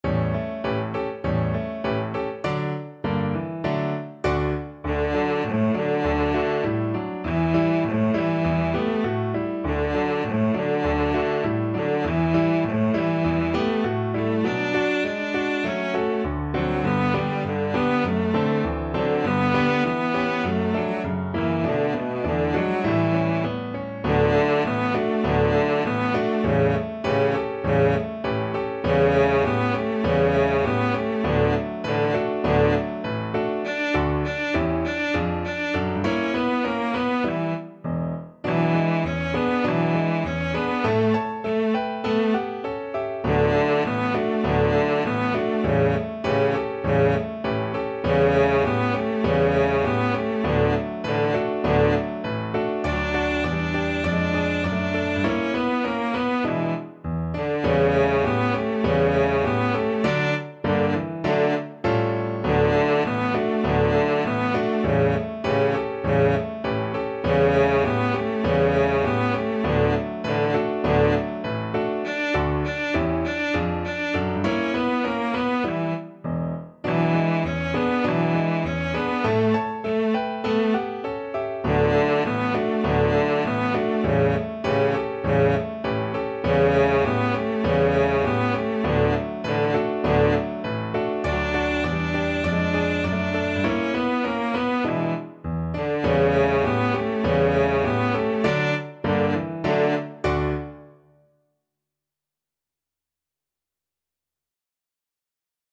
2/2 (View more 2/2 Music)
Moderato =c.100
Pop (View more Pop Cello Music)